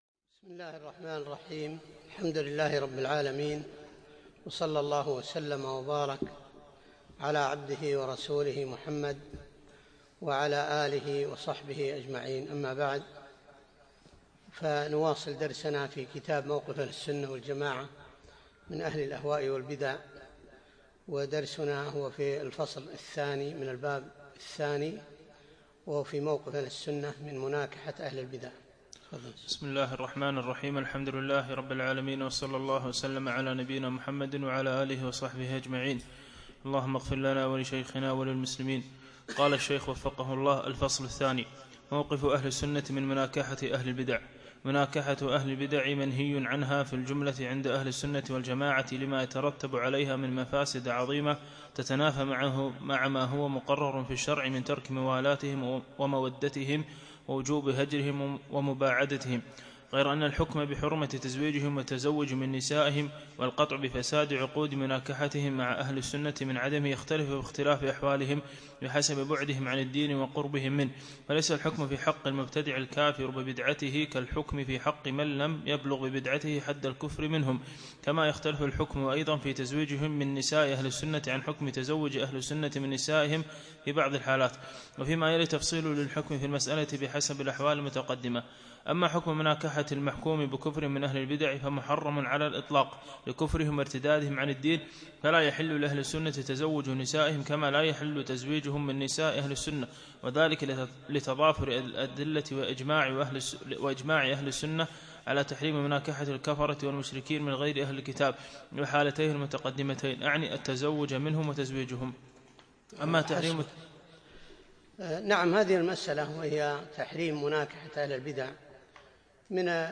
بعد العصر يوم الأربعاء 22 جمادى الأول 1437هـ الموافق 2 3 2016م في مسجد كليب مضحي العارضية